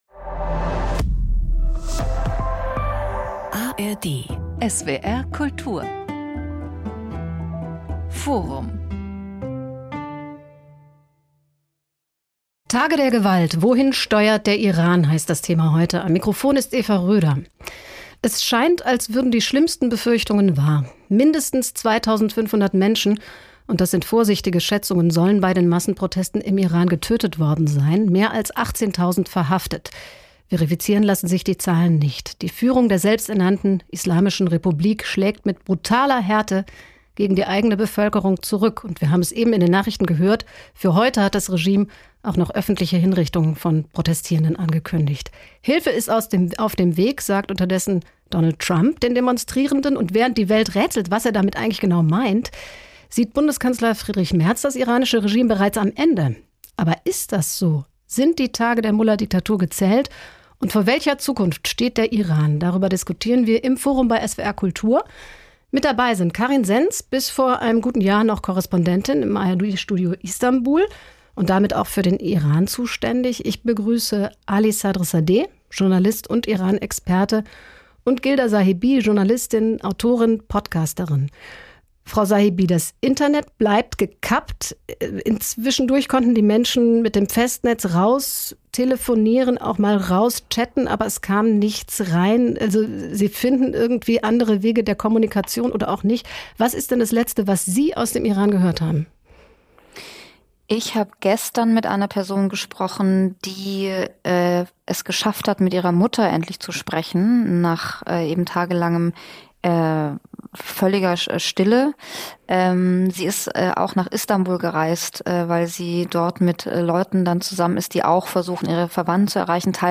Journalistin und Autorin